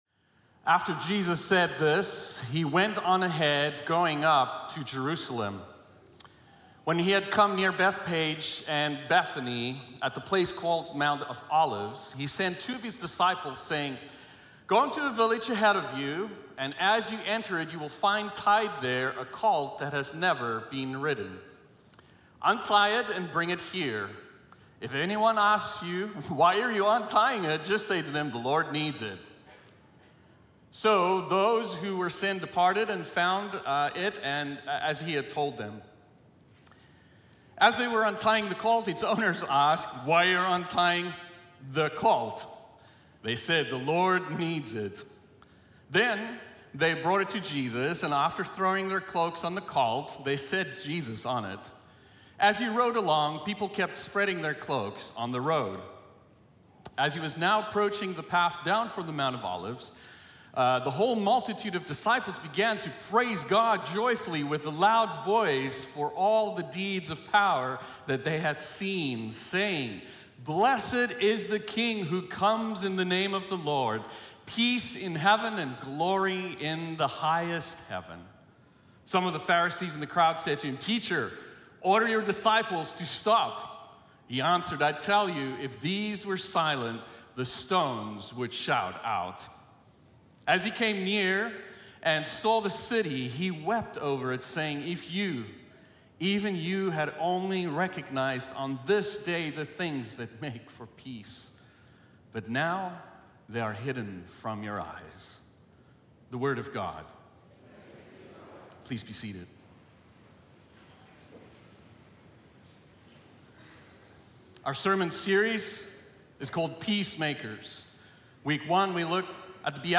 Sermon Archive | La Sierra University Church